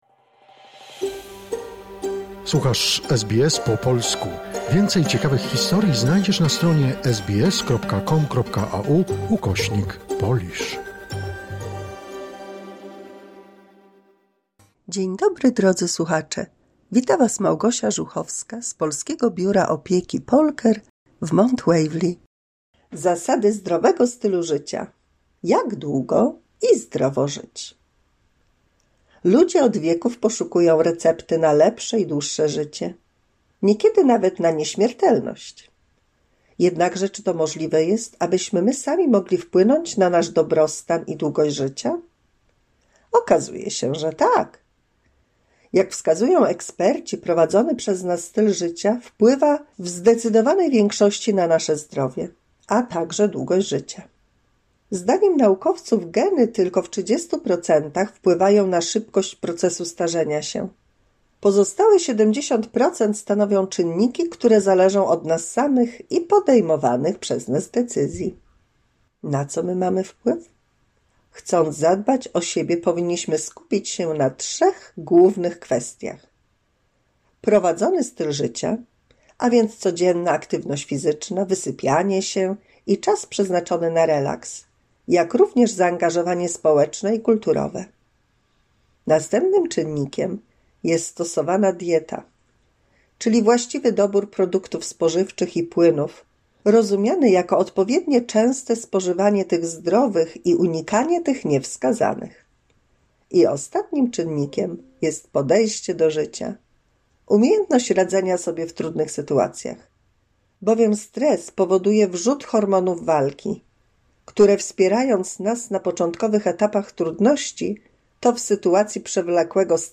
W 216 mini słuchowisku dla polskich seniorów usłyszymy o zasadach zdrowego stylu życia i Riese w Górach Sowich.